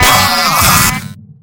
sentry_damage2.wav